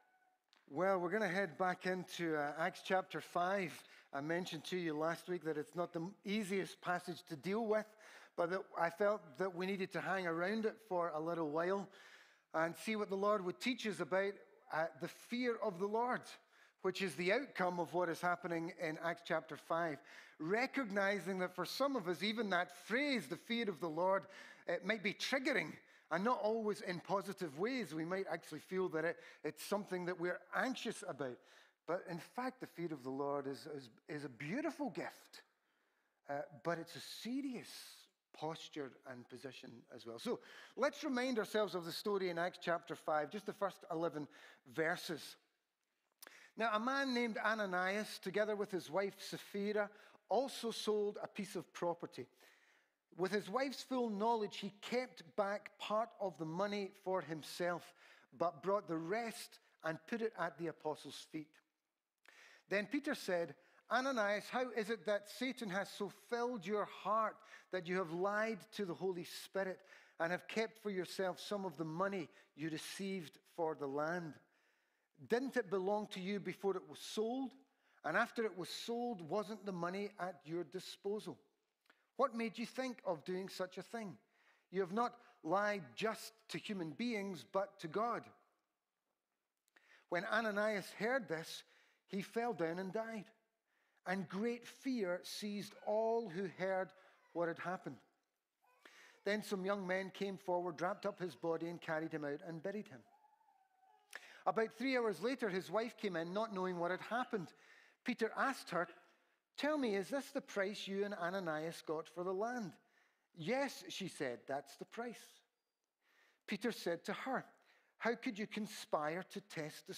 Sermons | Trinity Church of the Nazarene